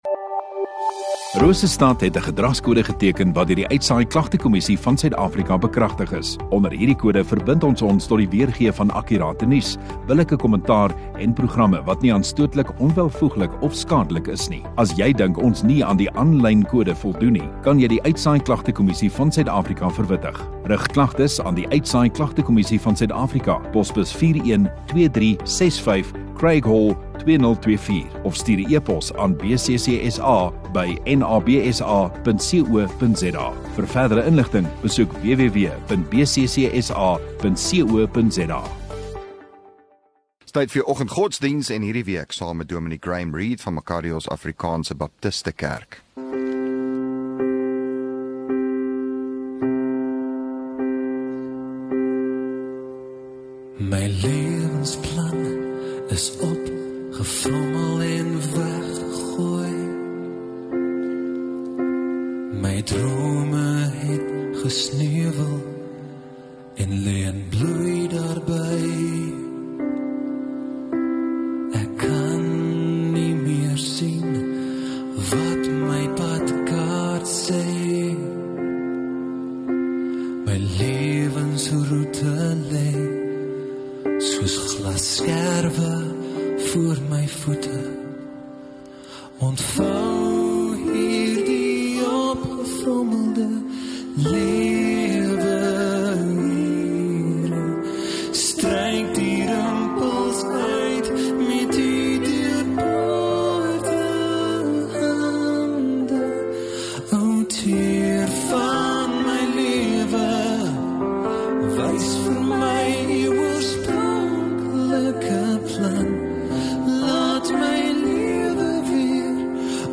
14 Apr Maandag Oggenddiens